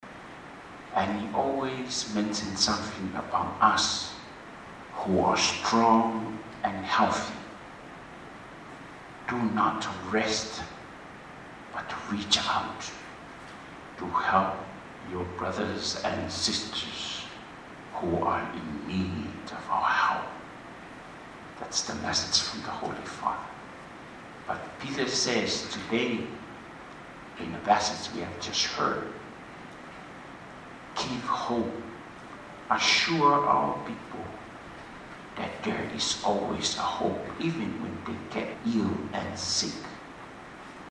The leader of the Catholic Church in American Samoa Bishop Kolio Etuale Tumanuvao offered the invocation and prayed for the successful outcome of the conference. He recalled a message from Pope Francis, whom he first met recently in Rome about never giving up hope.